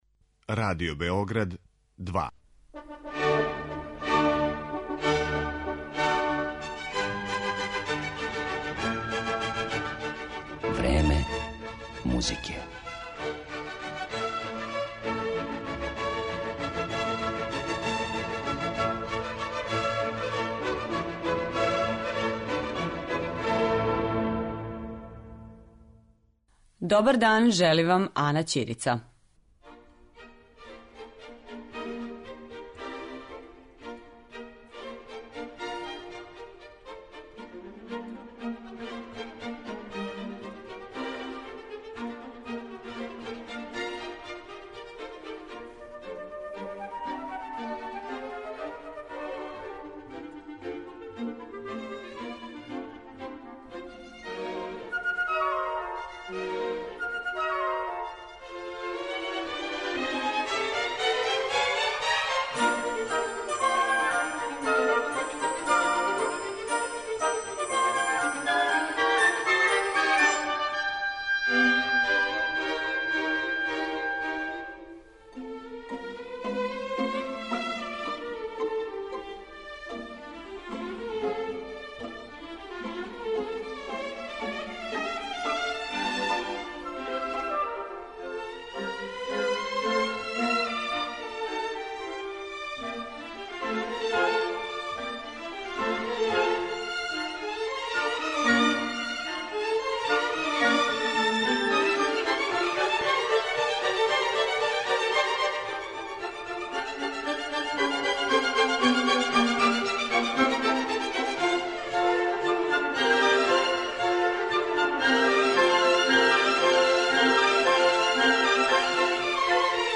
У данашњем, празничном издању Времена музике говорићемо о феномену "Крцка Орашчића" и слушати музику за балет Петра Чајковског у интерпретацији орекстра театра "Марински", под управом Валерија Гергијева.